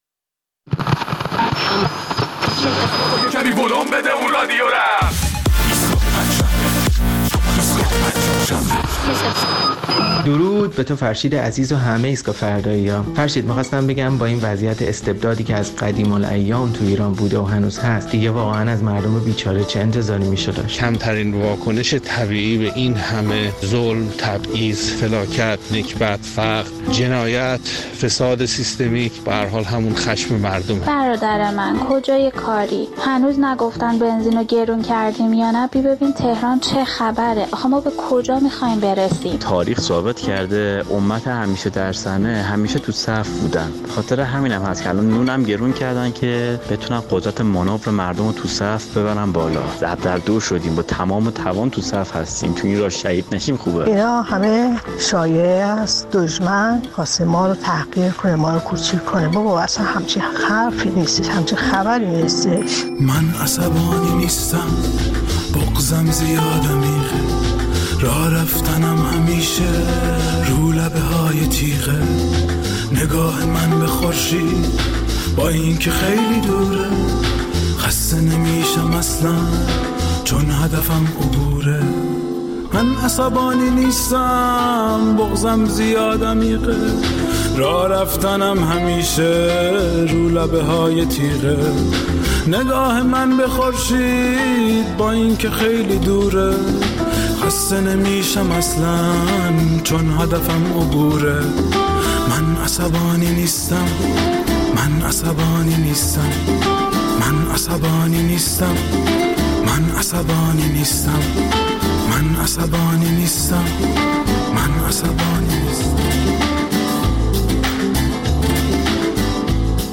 در این برنامه ادامه نظرات شنوندگان‌مان را درباره چرایی گسترش خشم و خشونت در جامعه می‌شنویم.